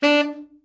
TENOR SN  19.wav